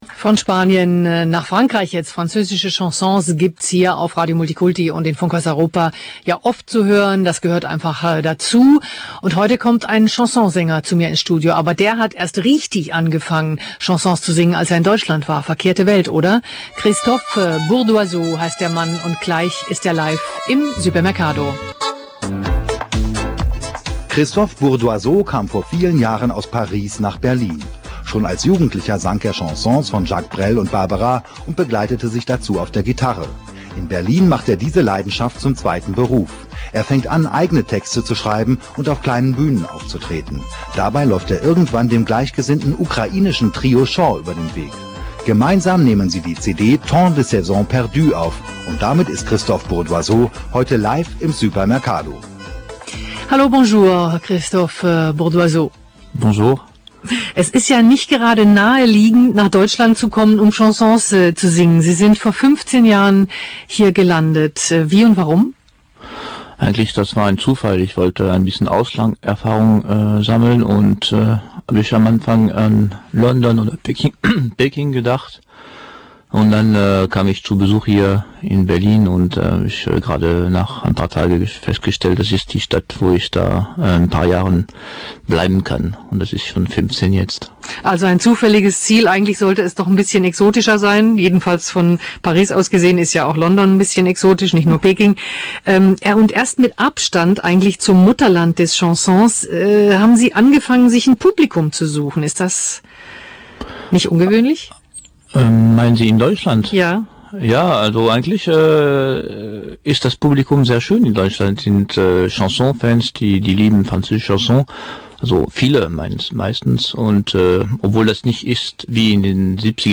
Französischer Sänger
Interview bei Radio Multikulti